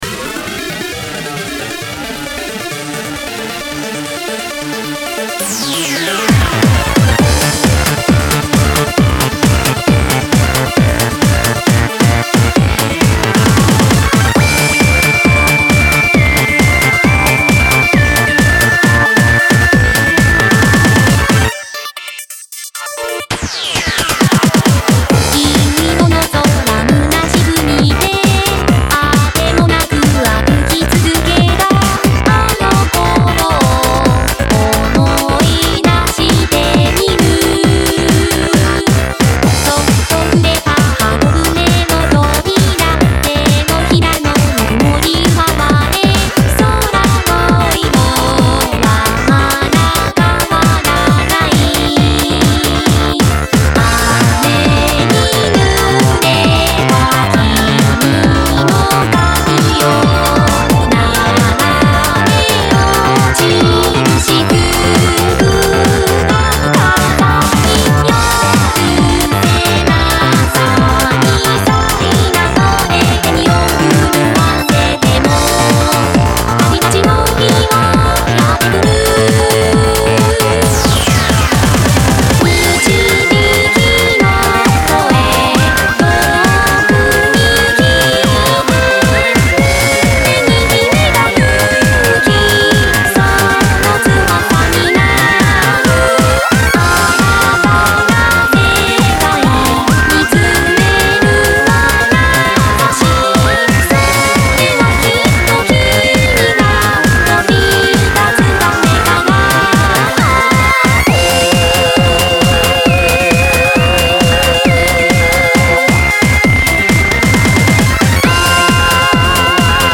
【VY1】Silver Dusk【オリジナル】 今度は90年代アニソン風味のラインでございます。リバーブ効きまくりのパイプオルガンの音を使いたかったので、ブレイクにフィーチュアしました。
VOCALOID